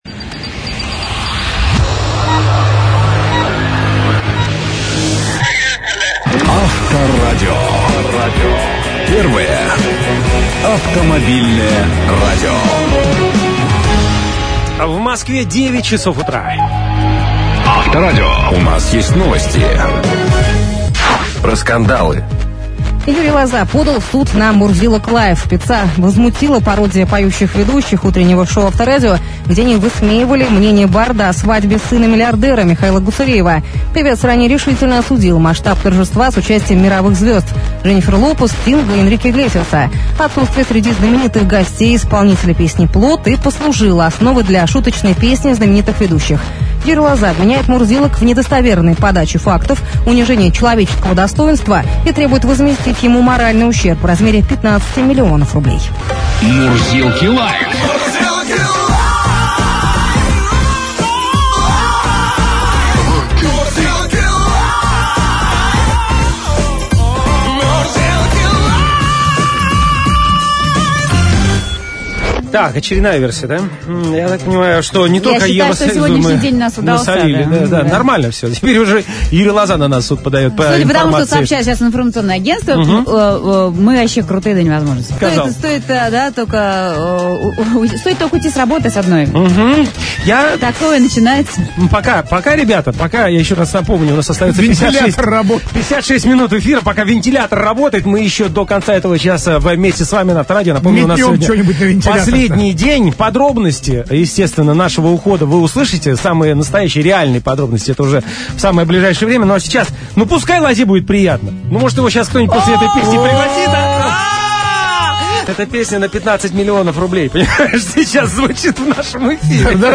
Радиоведущие.ру попробовали сохранить для потомков фрагмент этого события – День дурака в московском радиоэфире удался!